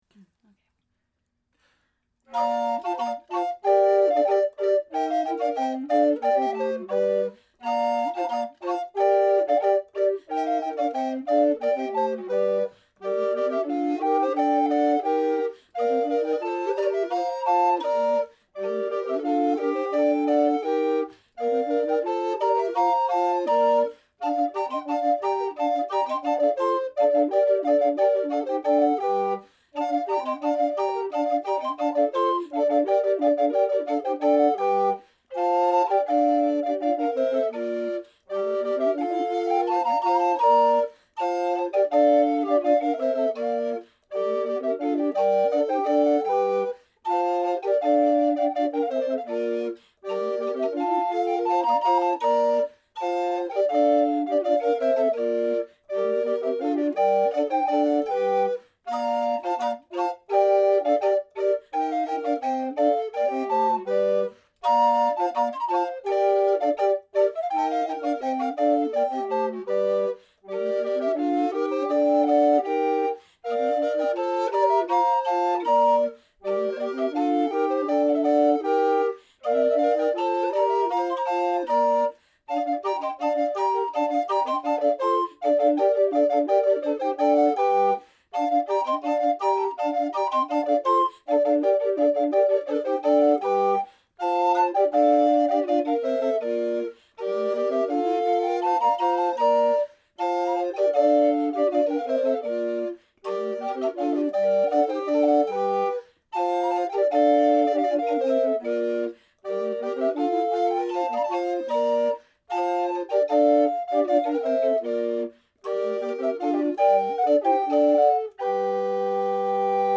Music from the 15th and 16th centuries
recorders
at the Loring-Greenough House, Jamaica Plain